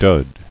[dud]